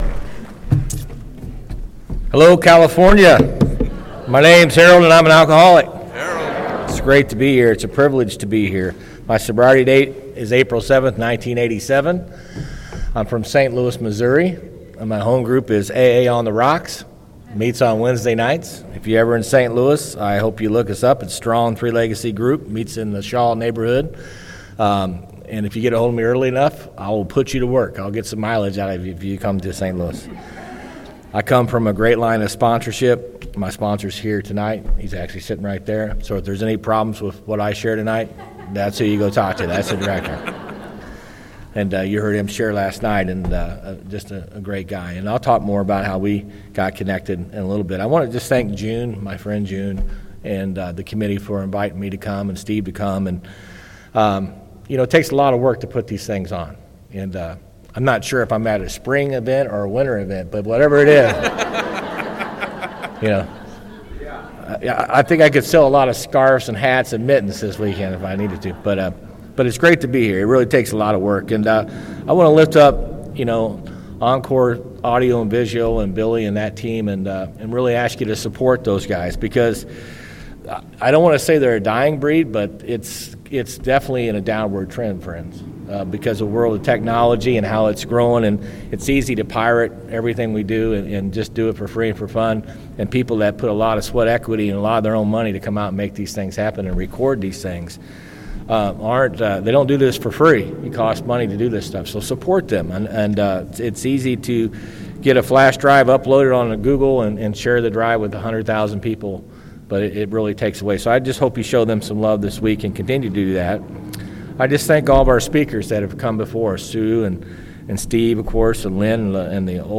Saturday Night Main Speaker &#8211